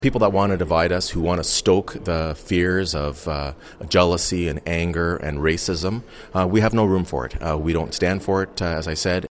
2-MAYOR.mp3